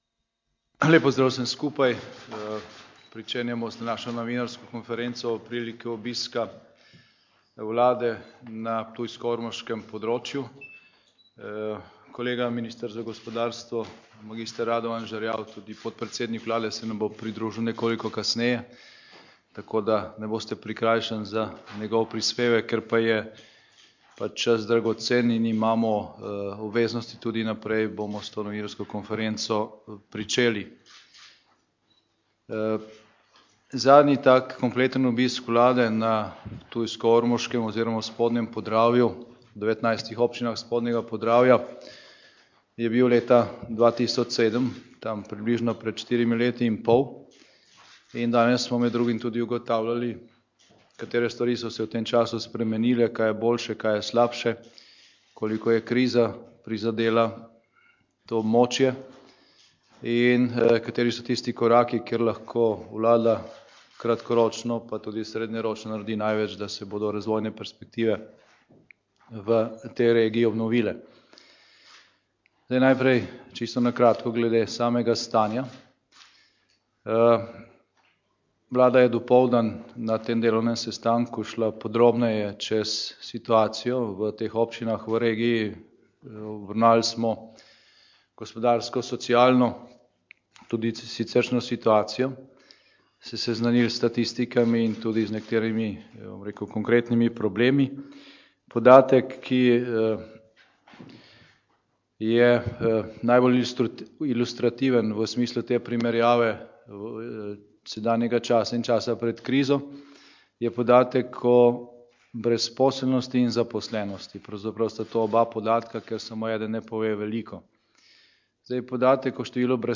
Kot je dejal na popoldanski novinarski konferenci, je namen tokratnega obiska v tej regiji ugotoviti, koliko je kriza v teh štirih letih in pol, kar je bila vlada nazadnje na obisku v 19 občinah Spodnjega Podravja, prizadela regijo. Obenem je vlada želela ugotoviti tudi, kateri so tisti koraki, s katerimi se lahko v dolgoročnem ali kratkoročnem smislu naredi največ za to, da se bodo razvojne perspektive v regiji obnovile.